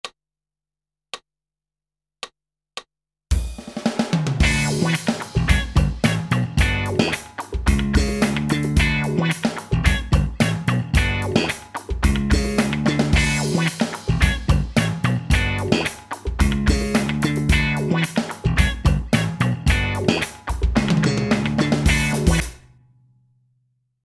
音源を聞くと分かるようにドラムもベースもギターも赤丸の所にアクセントを付けている。
lesson1slap.mp3